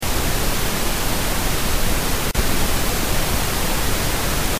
Le bruit rose est un bruit contenant absolument toutes les fréquences avec une énergie constante par octave. Dans le fichier ci-joint, il est envoyé alternativement à gauche et à droite. Il y a de fortes chances pour que vous perceviez une couleur de son différente des deux côtés.
Bruit rose L_R.mp3